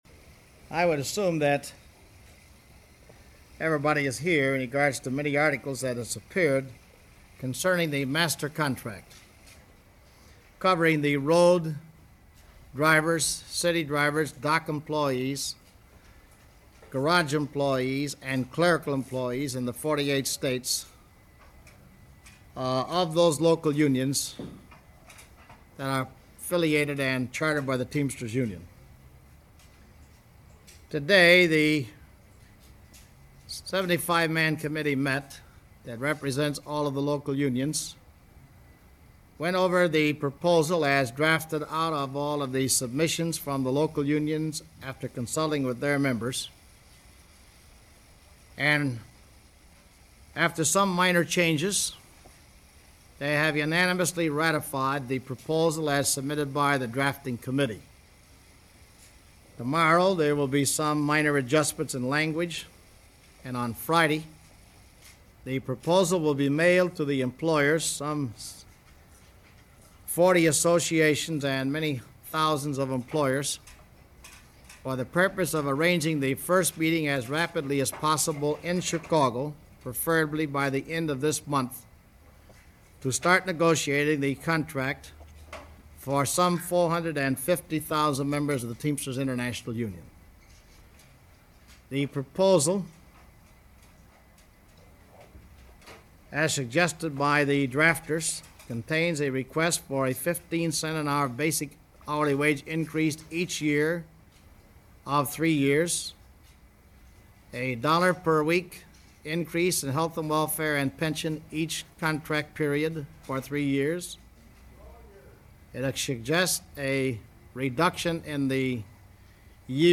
A Jimmy Hoffa Press Conference -1963 - Past Daily Reference Room
Not a cliffhanging Press Conference or one loaded with drama and accusations, but rather a typical press conference conducted by Teamsters President Jimmy Hoffa in November 6, 1963 on the occasion of answering questions regarding the National Contract, a single contract for all Teamsters locals throughout the country, beginning in 1964.